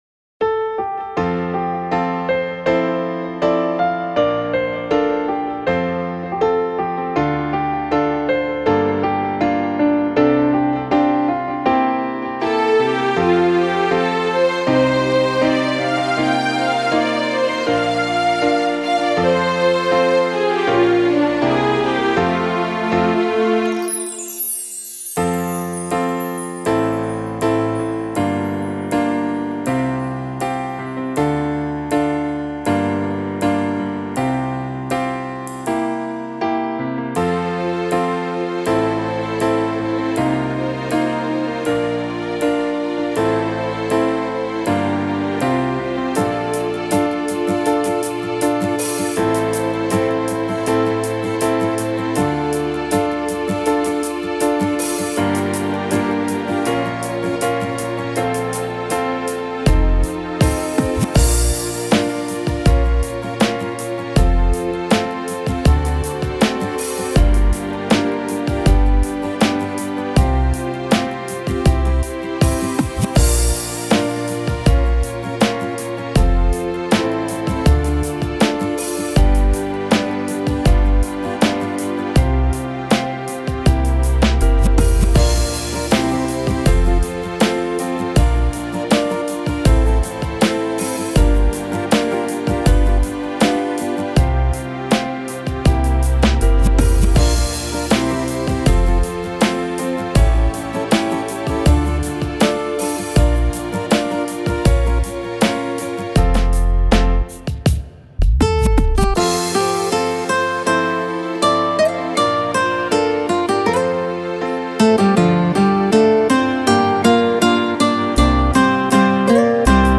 Tone Tốp (F)